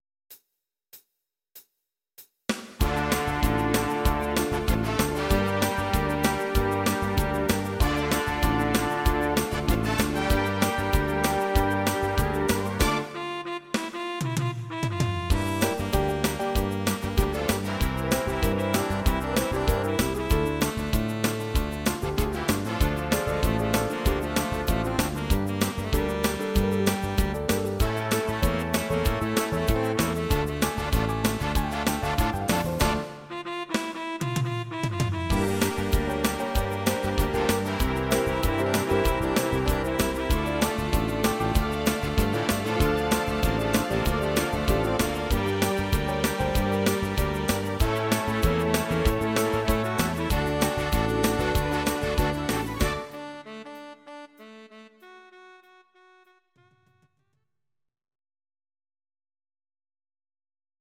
Audio Recordings based on Midi-files
Pop, Rock, Irish Music, 1970s